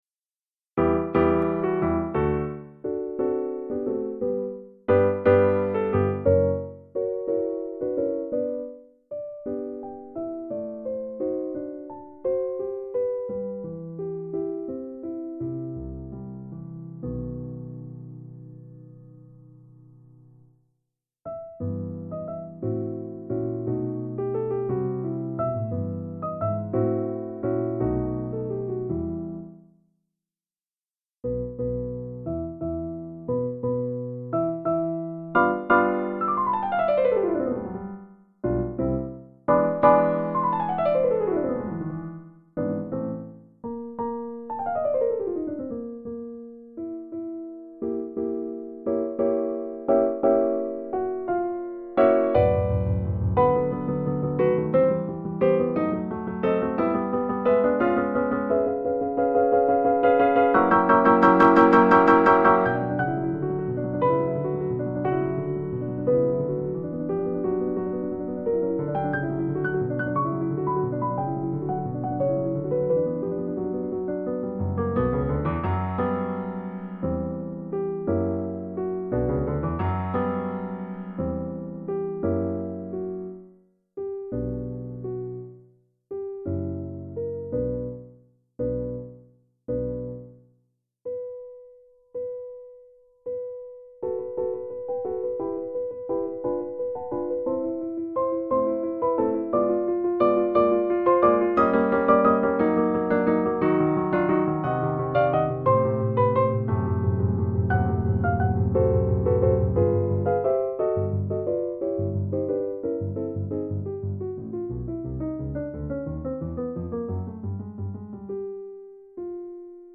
The midi file was downloaded from The Classical Music Archives.